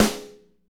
SNR MTWN 08L.wav